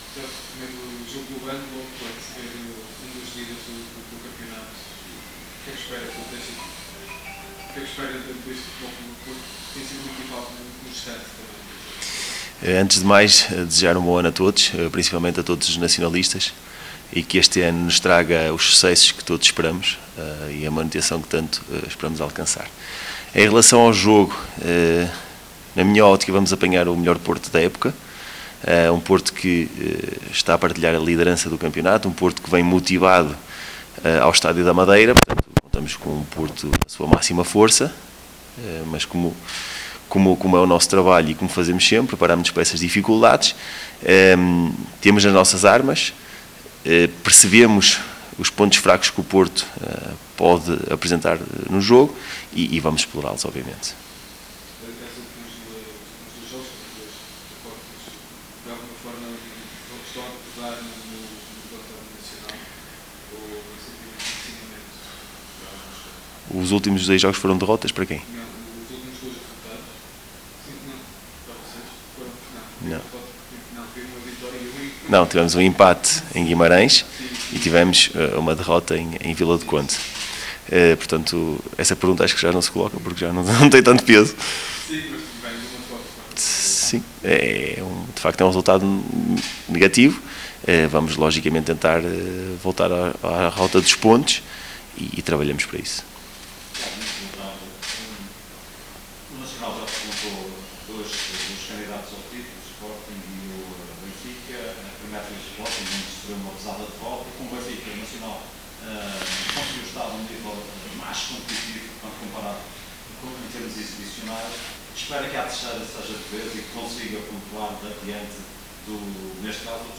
Na conferência de antevisão